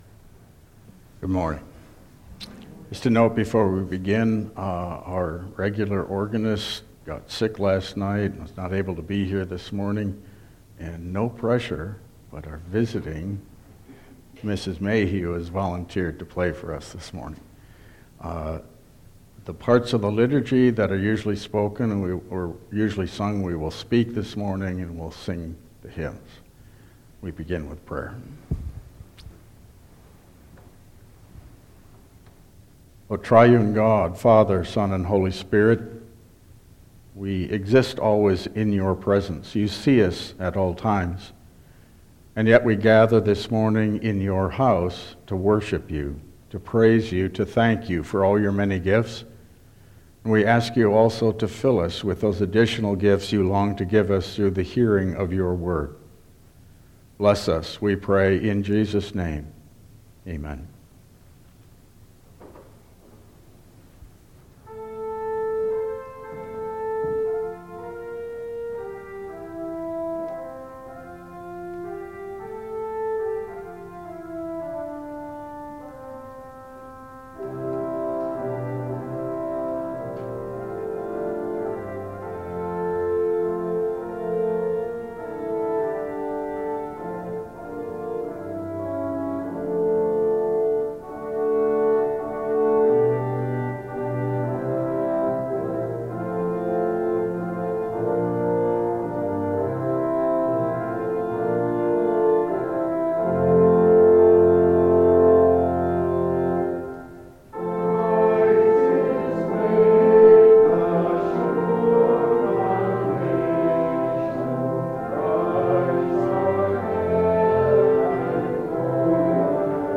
Download Files Printed Sermon and Bulletin
Service Type: Regular Service